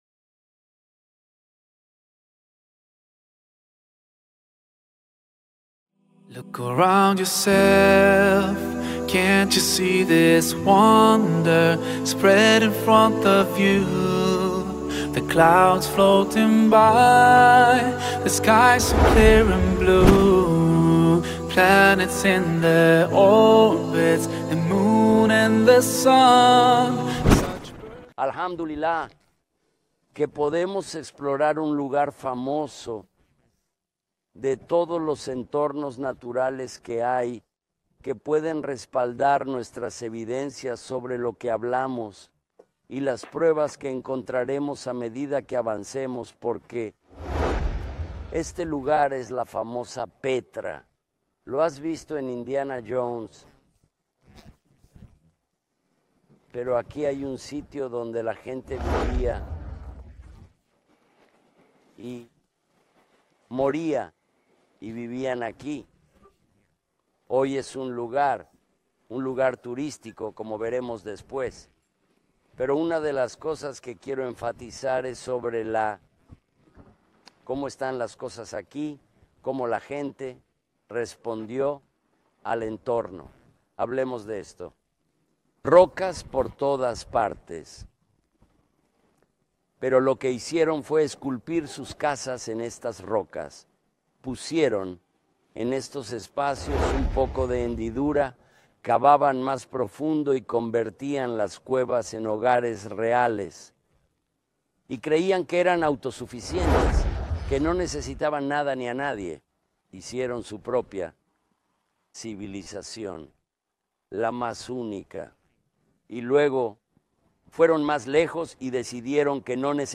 filmada en paisajes escénicos y lugares históricos de Jordania. En este episodio, explica el primer pilar de la fe: la creencia en Dios.